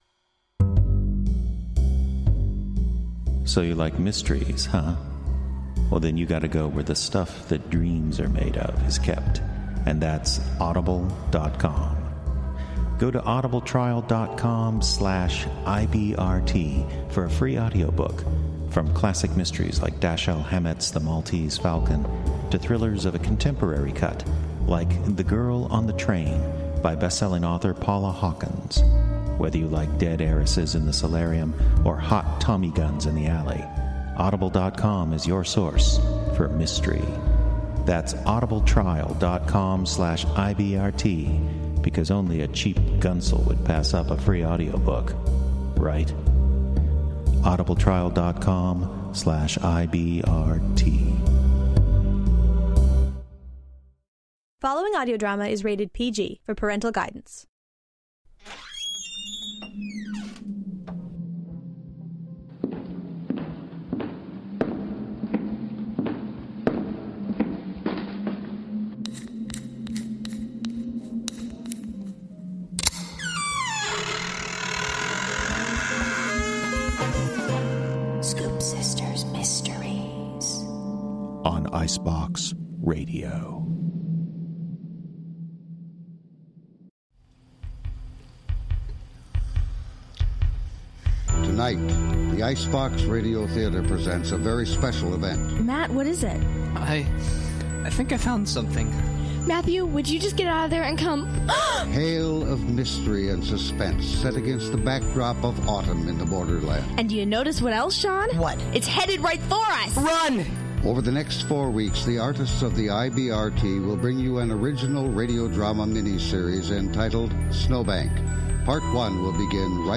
… continue reading 58 episodes # Theater # Arts # Audio Drama # Comedy # Icebox Radio Theater